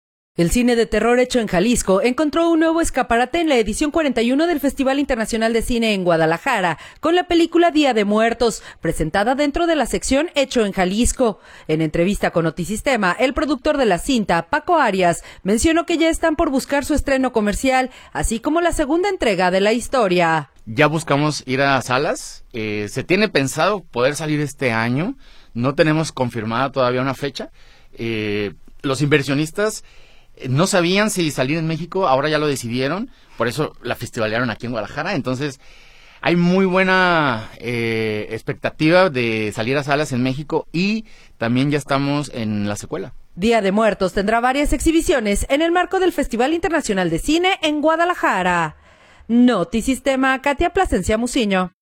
En entrevista con Notisistema